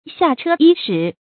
注音：ㄒㄧㄚˋ ㄔㄜ ㄧ ㄕㄧˇ
下車伊始的讀法